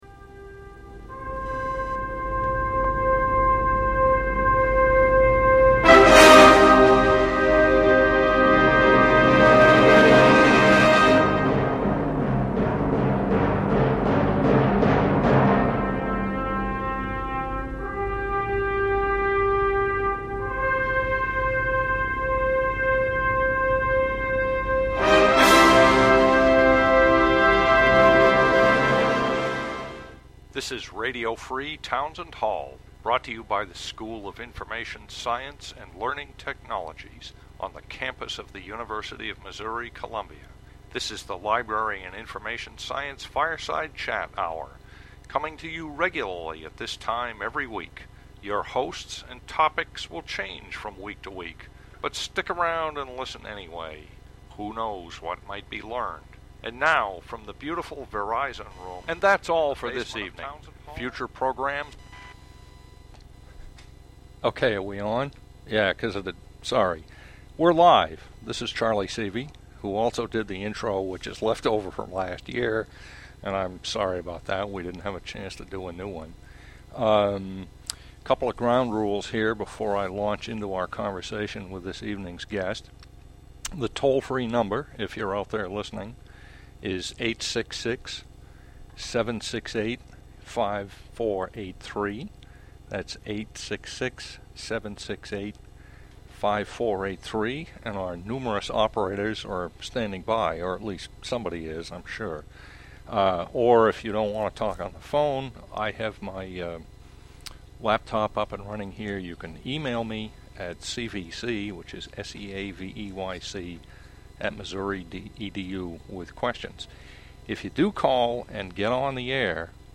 The first "First Tuesday" interview